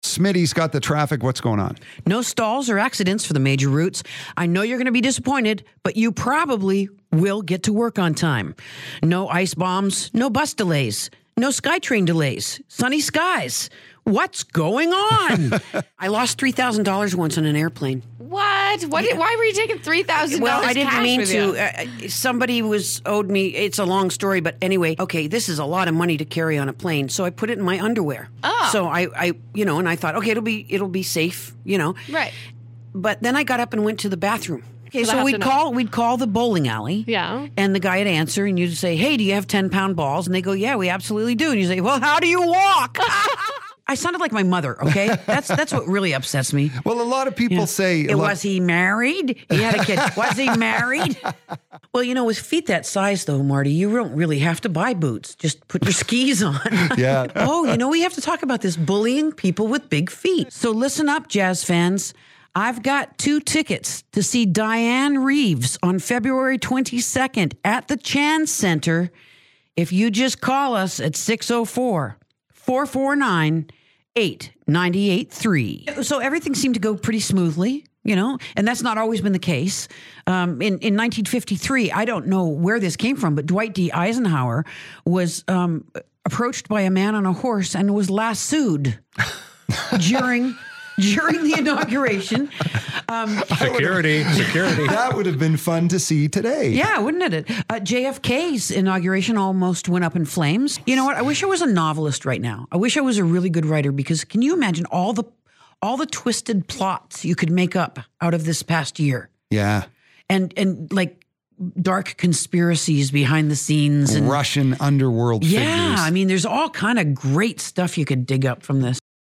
Radio Personality Demo